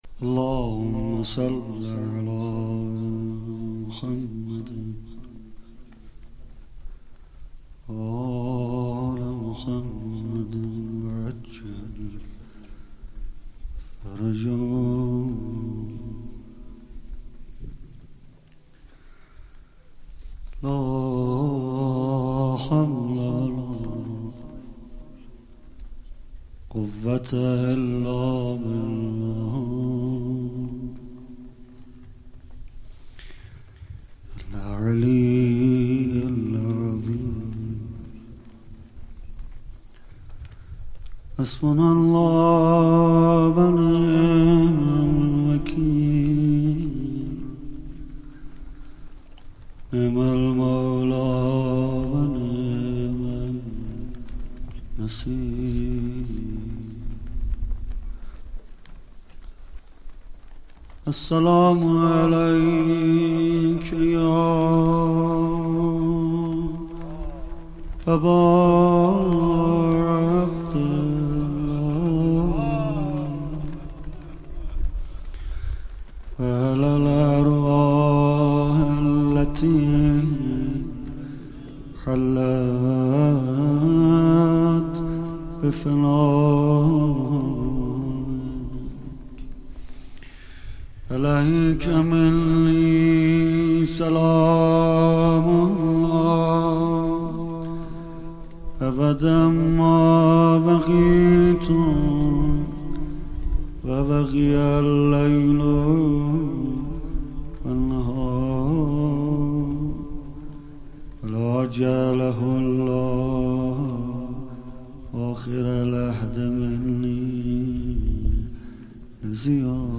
شب سوم محرم 1399هیات فداییان حسین(ع) اصفهان